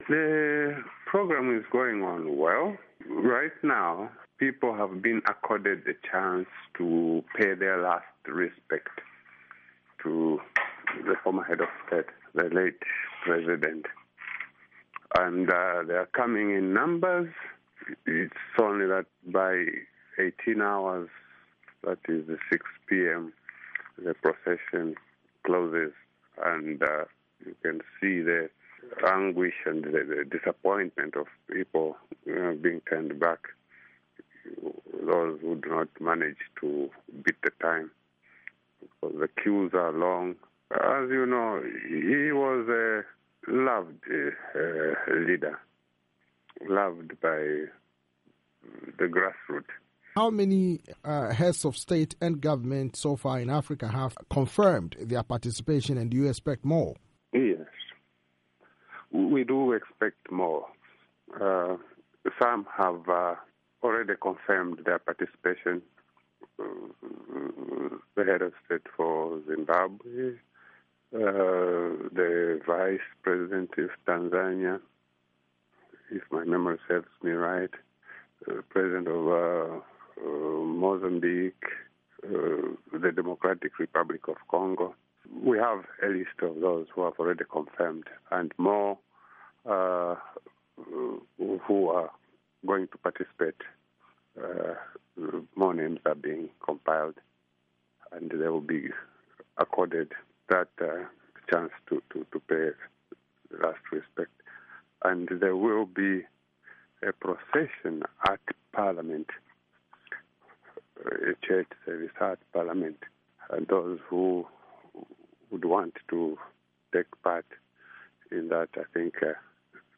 interview with Joseph Katema, Zambia's Information Minister